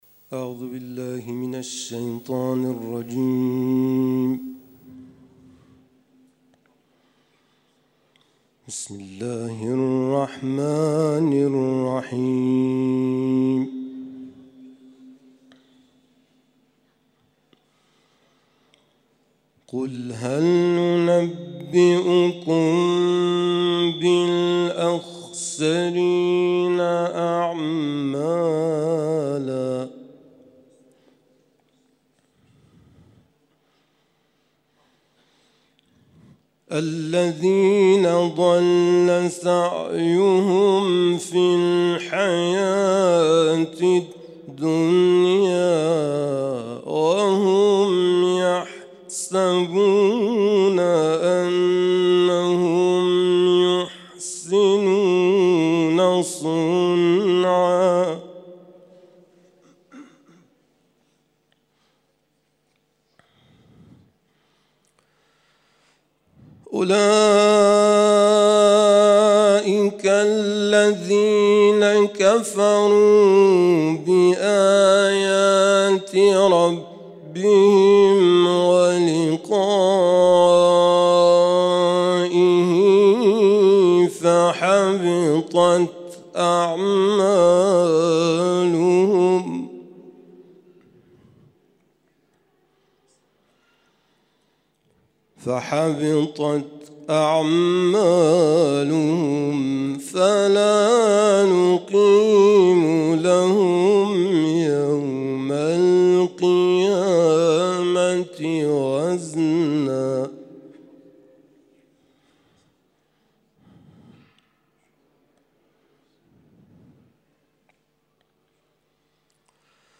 تلاوت مغرب
تلاوت قرآن کریم